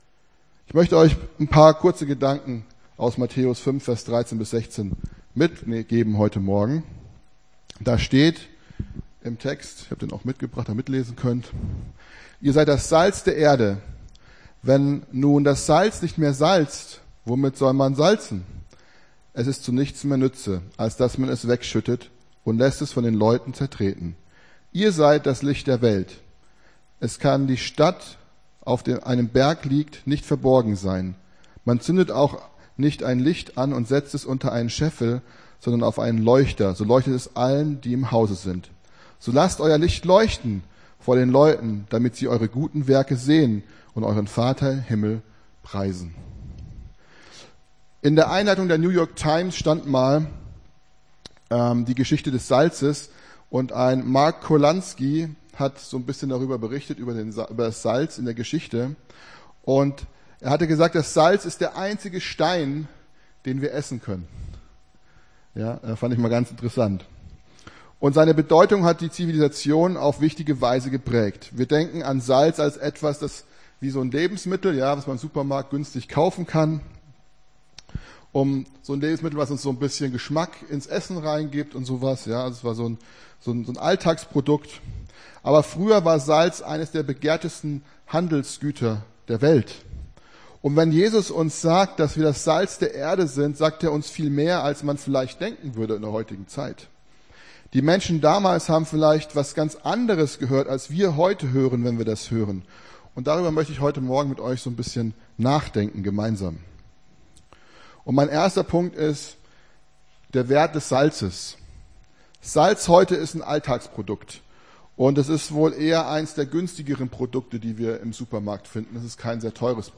Gottesdienst 28.04.24 - FCG Hagen